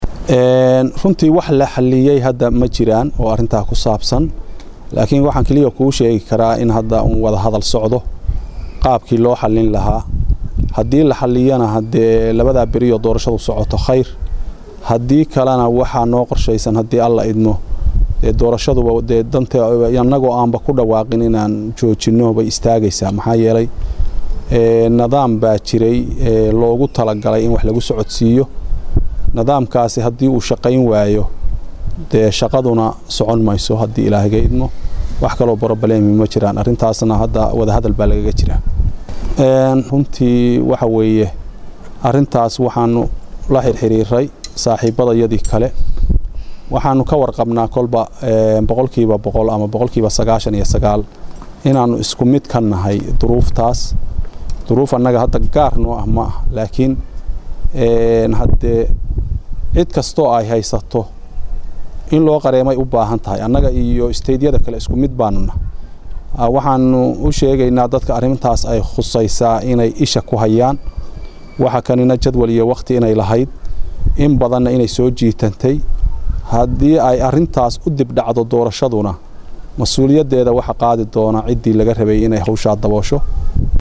Muqdisho(INO)- Guddoomiyaha Guddiga doorashooyinka Puntland Khaliif aw Cali oo Warbaahinta kula hadlay Magaalada Garoowe ayaa sheegay in guddigooda ay soo Wajahday dhaqaalo daro.